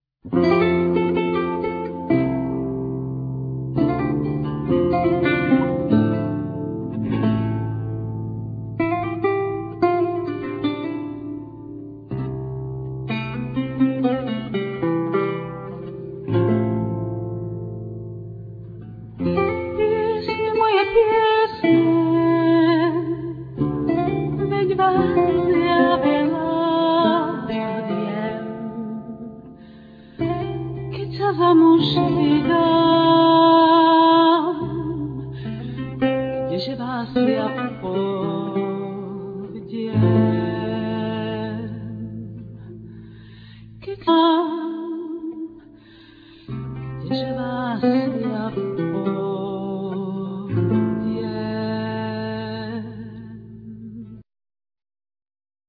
Vocals,Viola
Voclas,Guitar,Lute,Duduk,Harmonica,Percussions,etc
Viola da ganba
Percussions